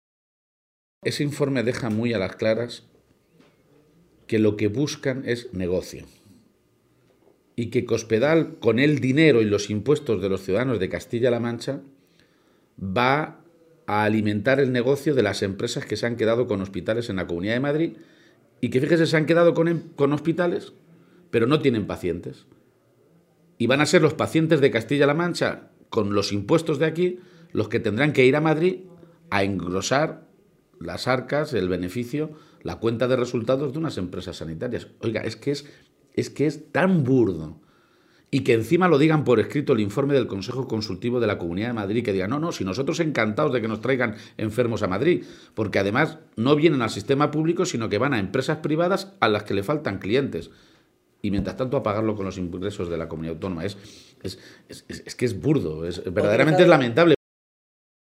Entrevista a García-Page en la Cadena Ser